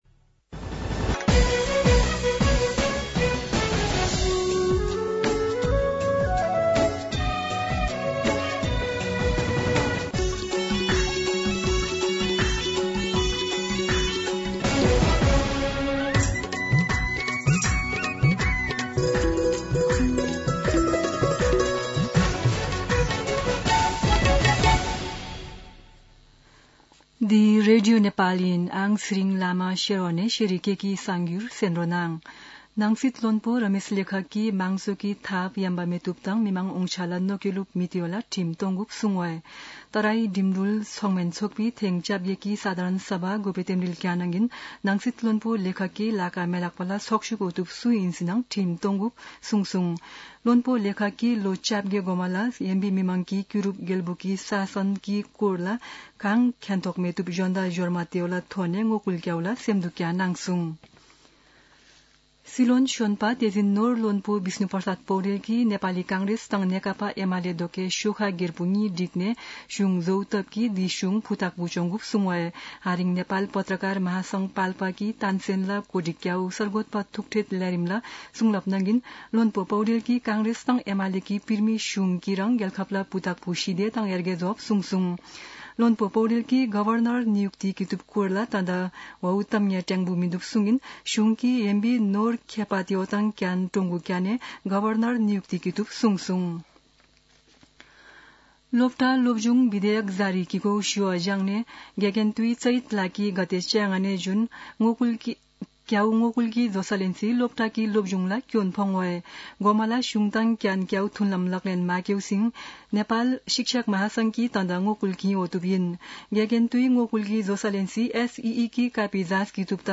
शेर्पा भाषाको समाचार : ३० चैत , २०८१
Sherpa-News-30.mp3